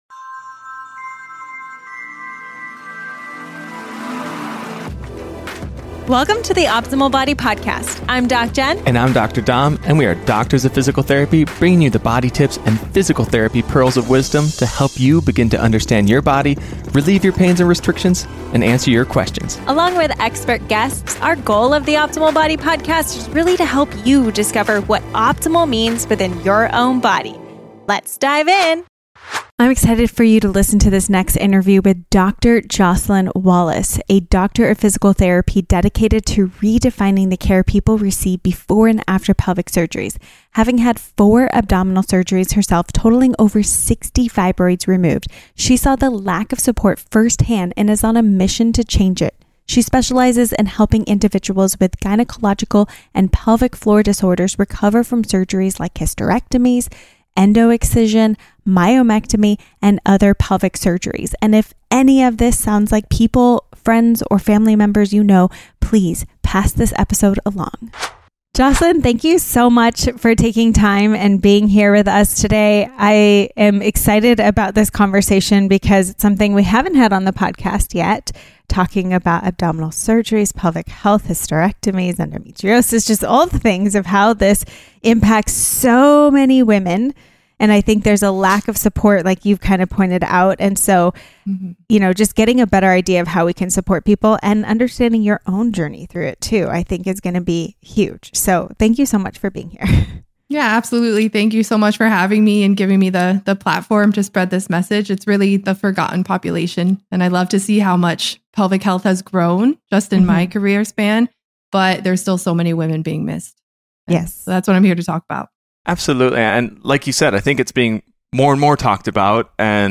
The conversation covers pelvic floor rehabilitation and pelvic floor therapy for pain and healing following abdominal surgery, such as myomectomy and hysterectomy.